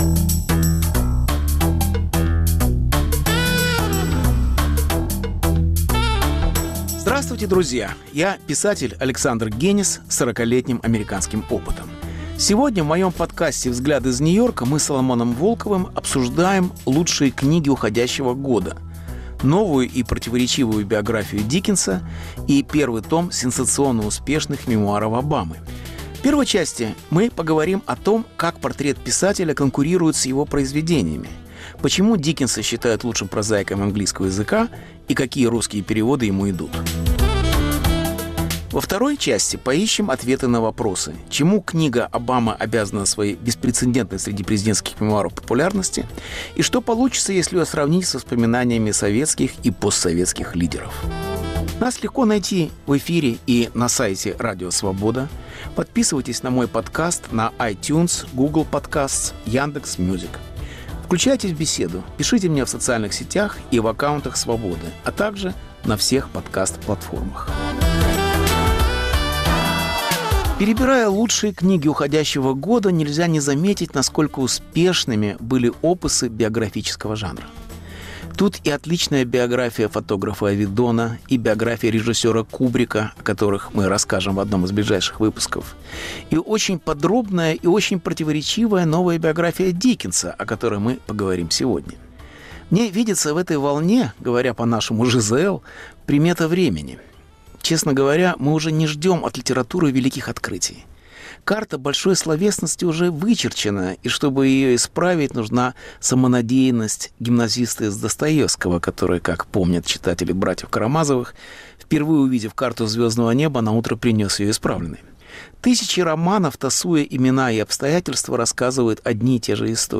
Беседа с Соломоном Волковым о лучших книгах года